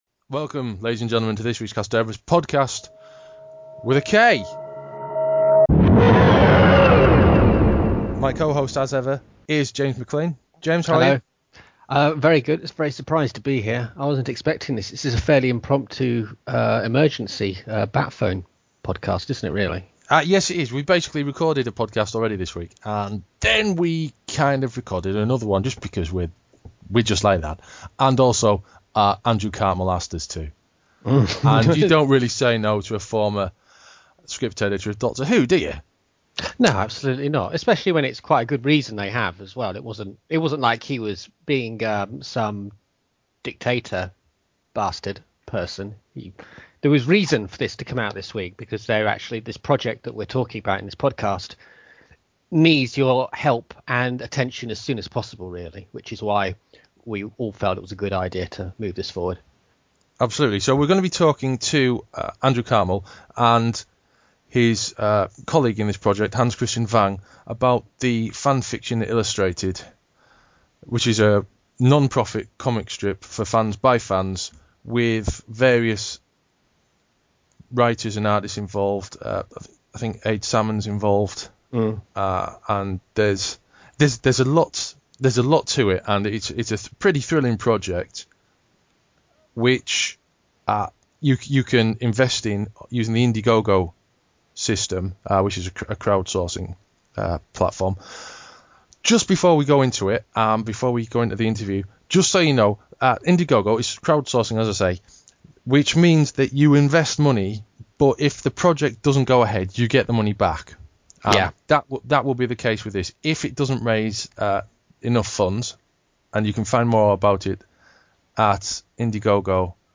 joins us this week to chat about a new project